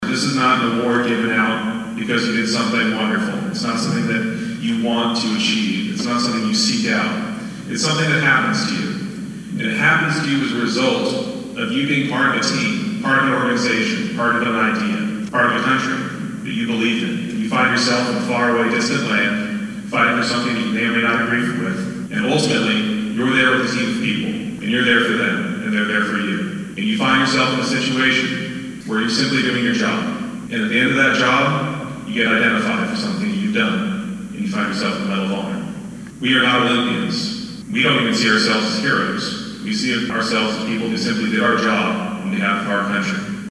Duxbury High School held a ceremony Wednesday paying tribute to two Medal of Honor winners.